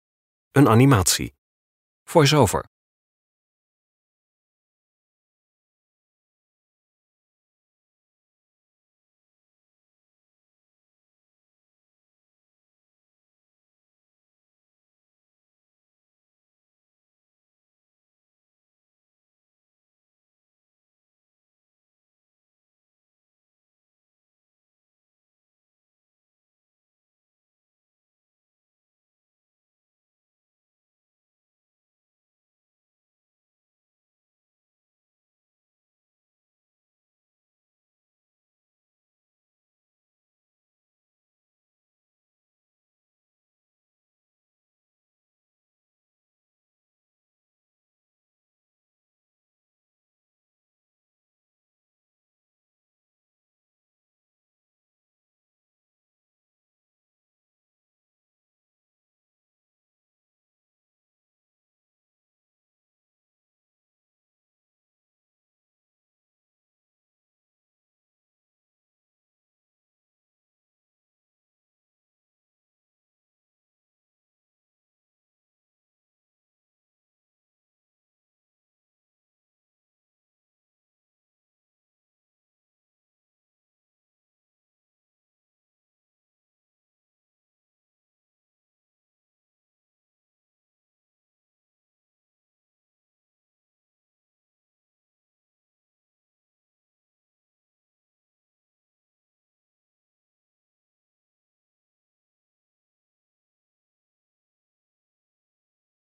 (Een animatie.)